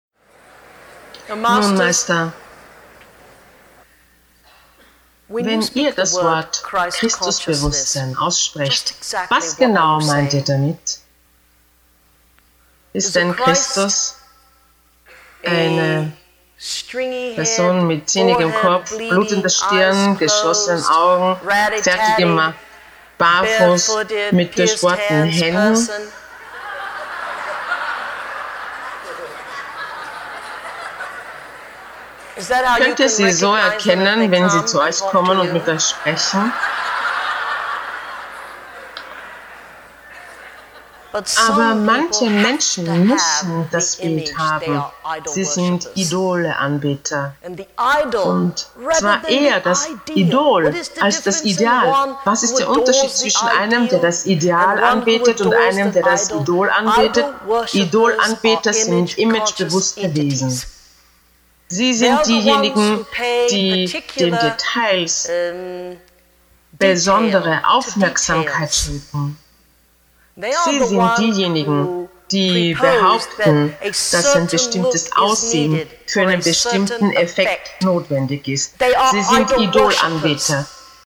Event: Advanced Evening – Moments with the Master